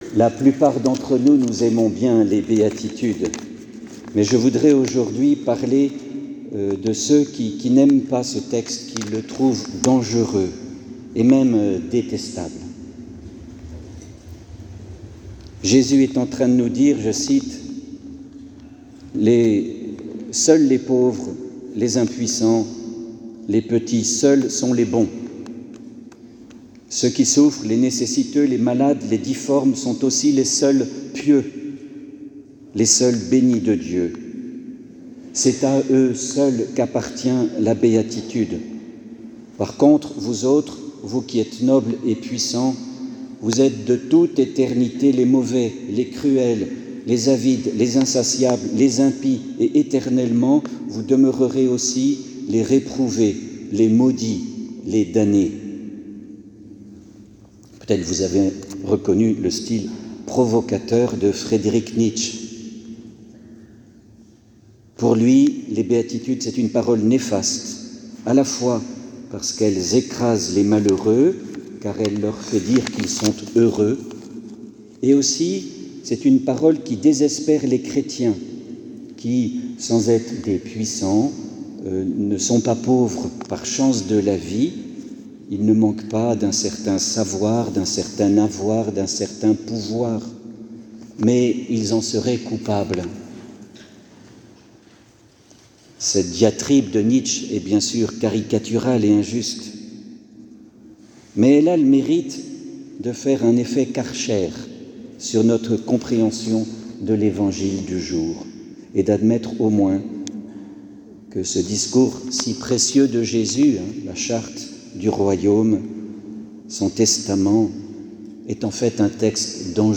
Homélie -Enregistrement audio